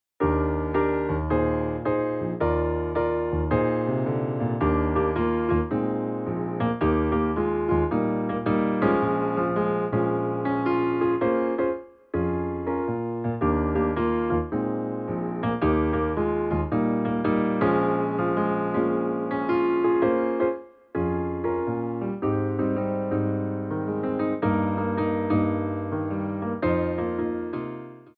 Piano Arrangements of Pop & Rock for Tap Class
SLOW TEMPO